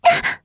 s025_Noraml_Hit.wav